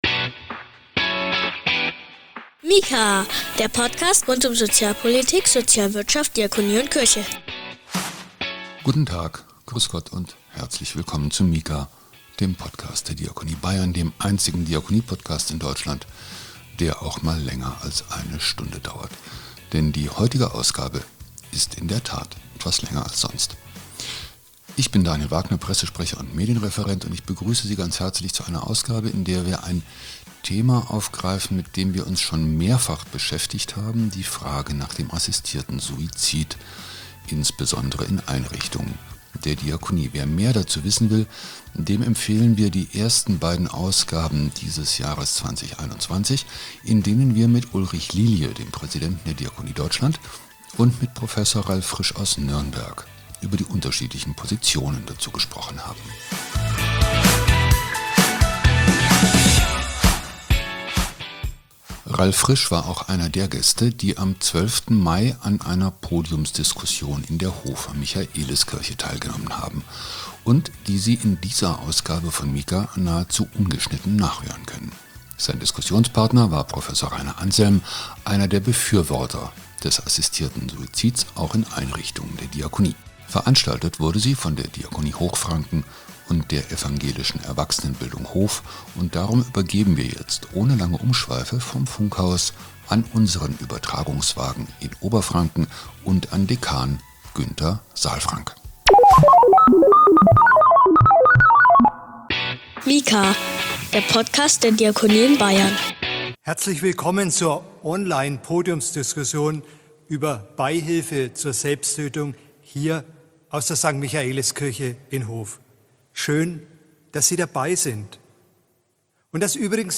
Diese Ausgabe von MIKA ist der Mitschnitt dieser Diskussion.